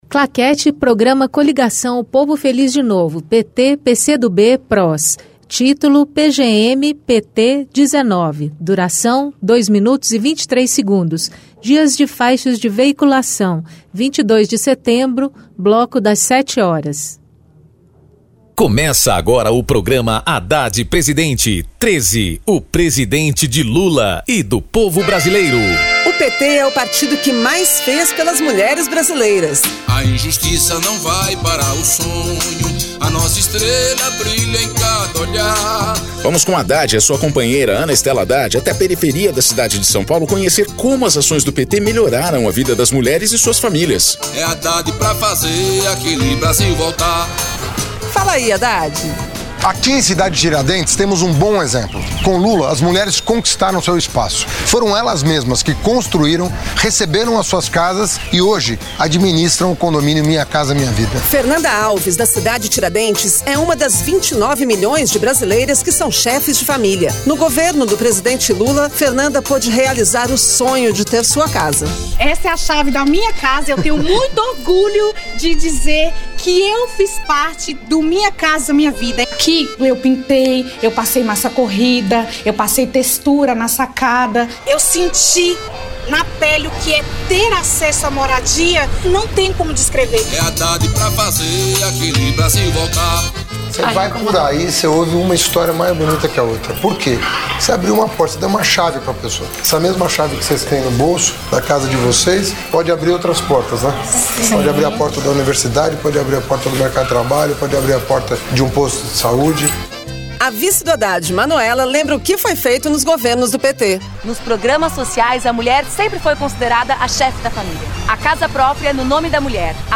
Gênero documentaldocumento sonoro
Descrição Programa de rádio da campanha de 2018 (edição 19) - 1° turno